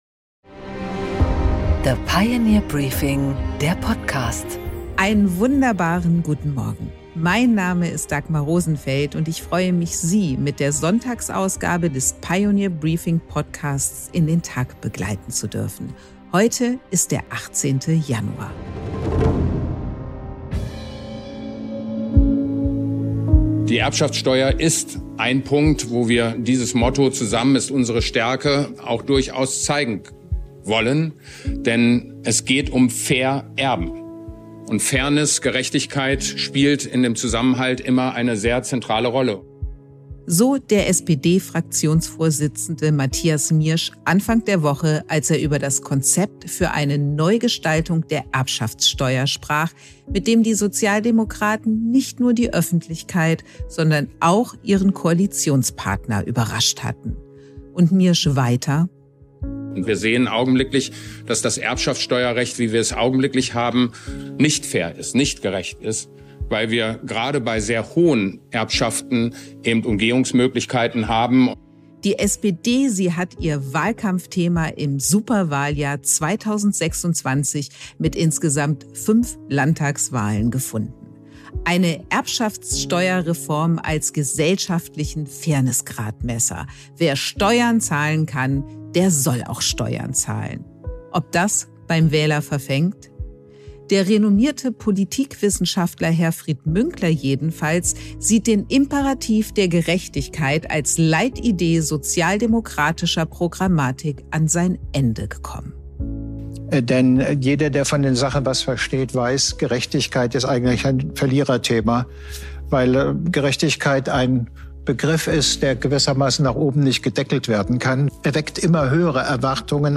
Dagmar Rosenfeld präsentiert die Pioneer Briefing Weekend Edition.